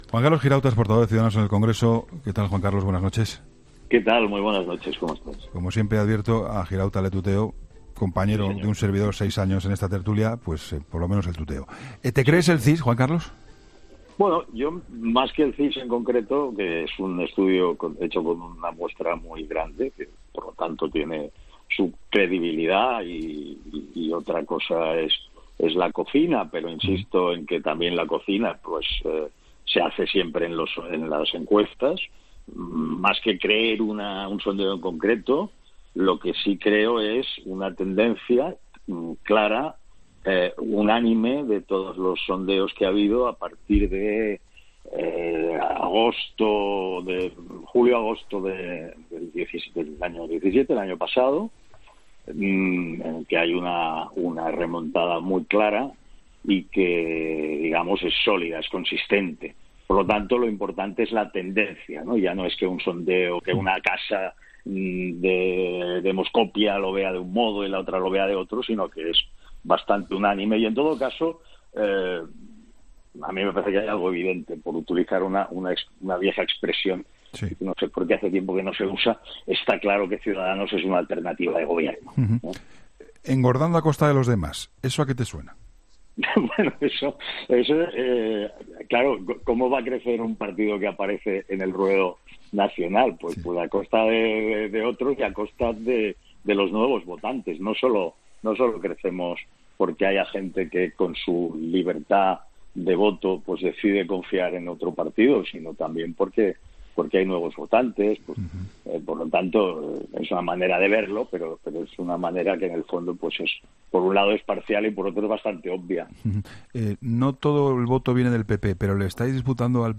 El portavoz de Ciudadanos en el Congreso, Juan Carlos Girauta, ha comentado en 'La Linterna' con Juan Pablo Colmenarejo los datos arrojados por la...